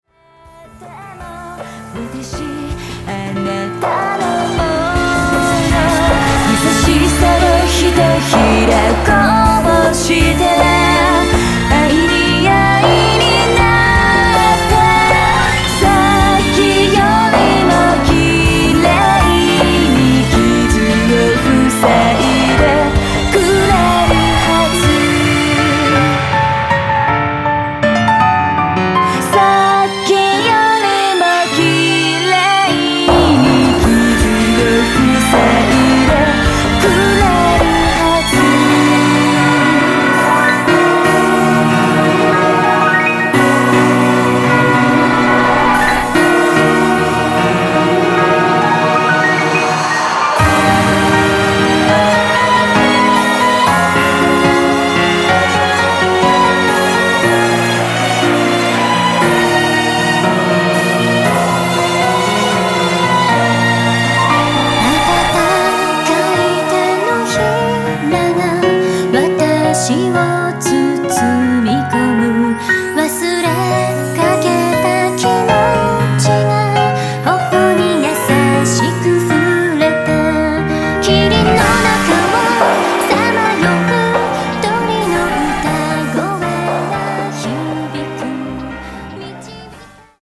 • 更に、新曲の書きおろし2曲を奔放かつ、繊細にアレンジし直し、メドレー仕立てで収録。
Piano Attractive